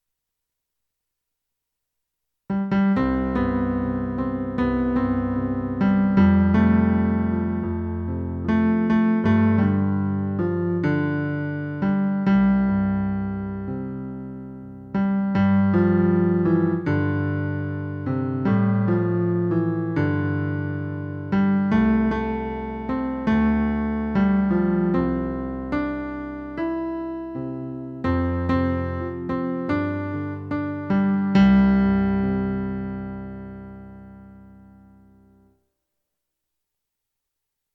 The Circle of Mercy - Men Harmony